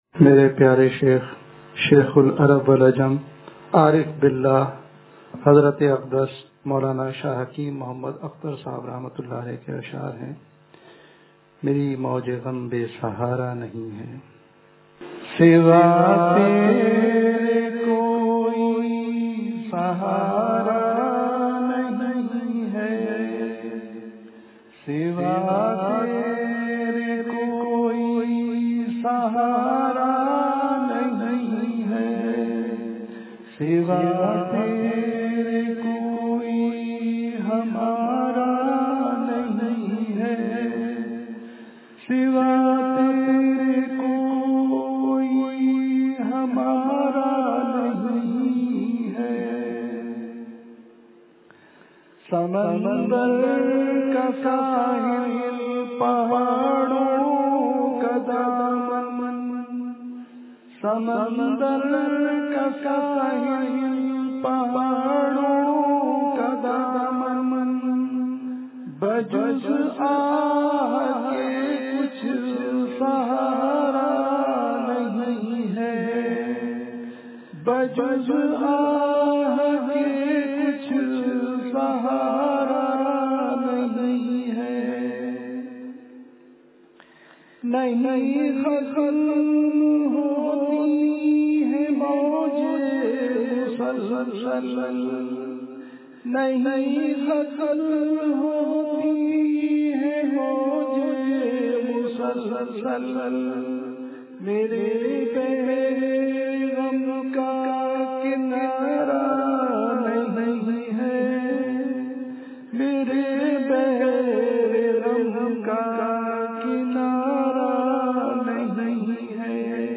بیان بعد نماز جمعتہ المبارک جامع بلال مسجد فیز 1 کوہسار ائیرپورٹ روڈ حیدرآباد